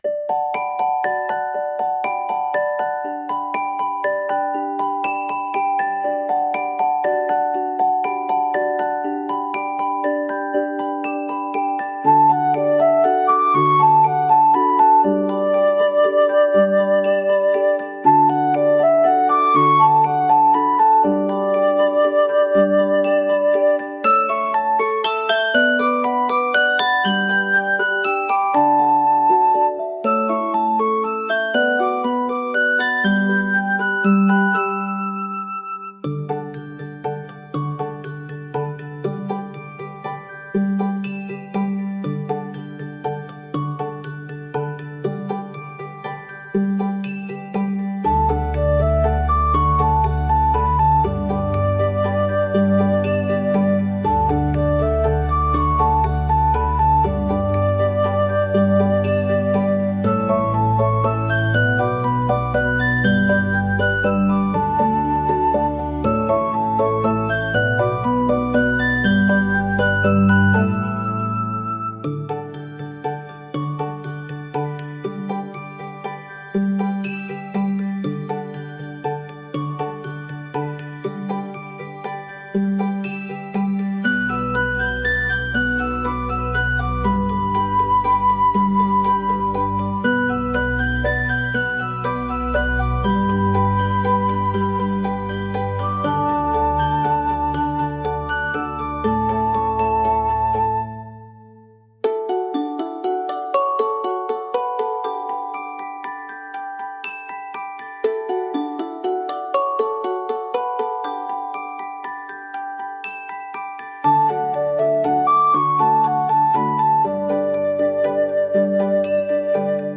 フリーBGM 🎶 木漏れ日の差すやさしい森をそっと歩くような、幻想的でメルヘンなBGMです。